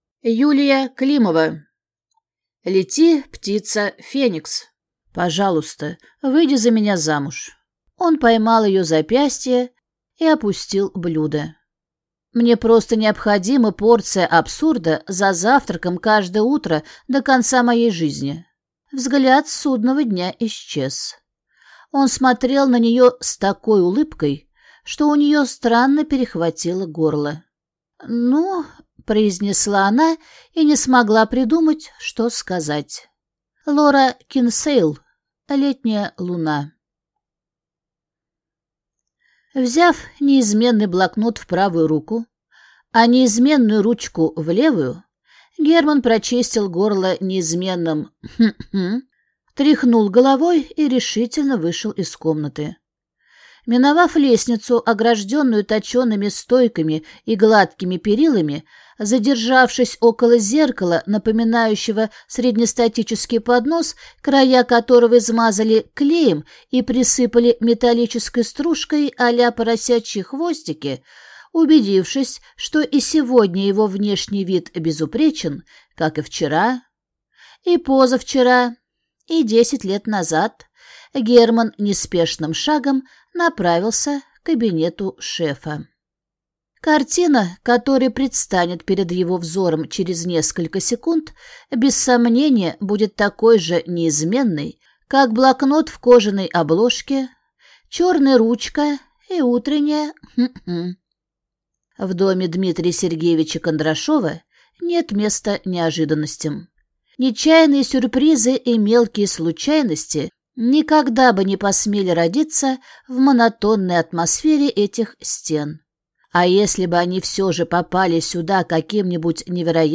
Аудиокнига Лети, птица Феникс | Библиотека аудиокниг